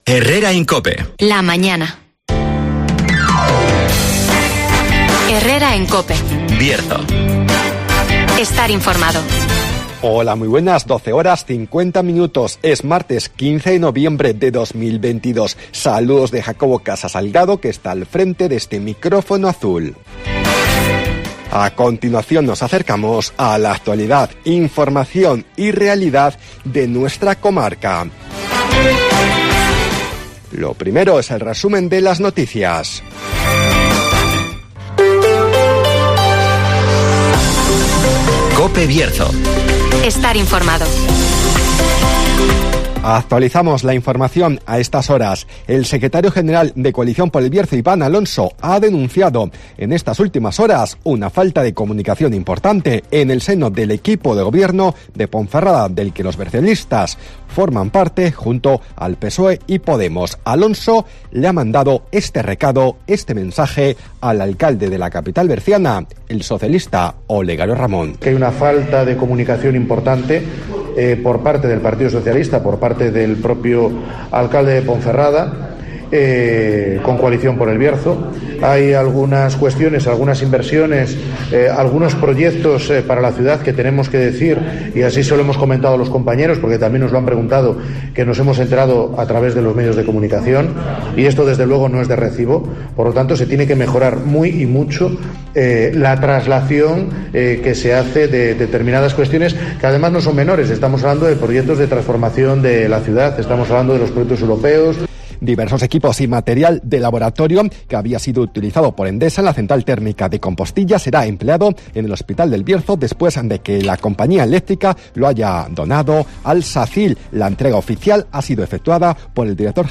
AUDIO: Resumen de las noticias, el tiempo y la agenda